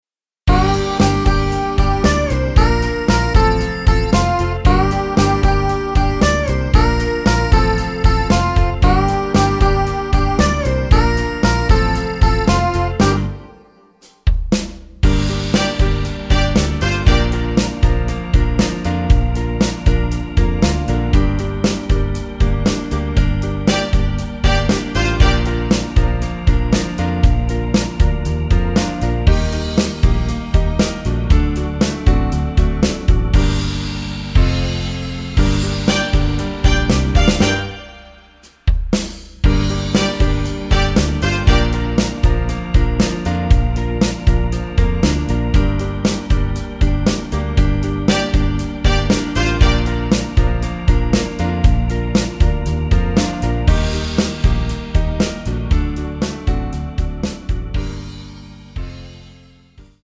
Gm
앞부분30초, 뒷부분30초씩 편집해서 올려 드리고 있습니다.
중간에 음이 끈어지고 다시 나오는 이유는